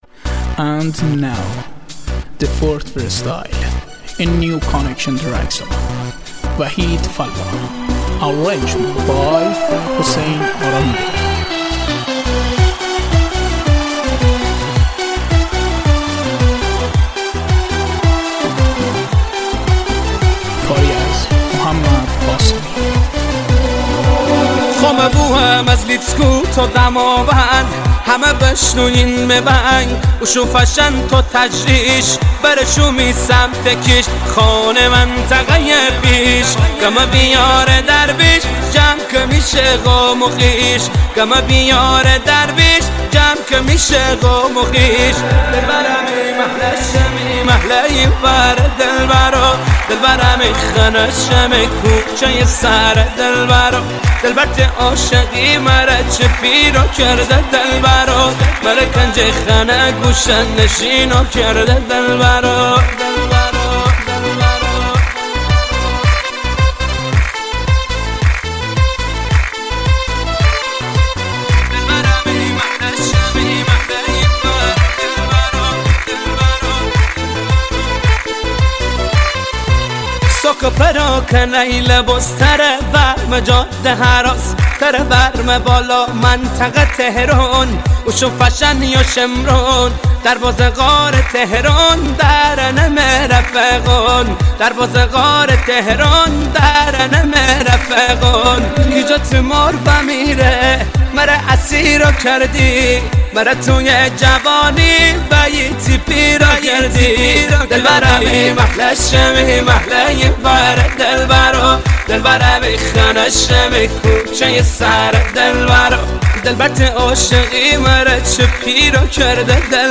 مازندرانی لاتی آهنگ امل زندون رفیق قصه تو بنده